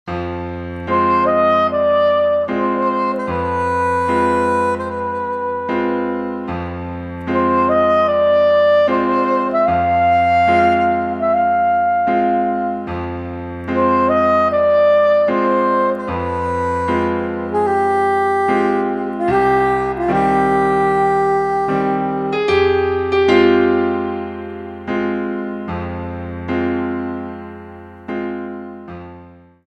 Saxophone en Sib et Piano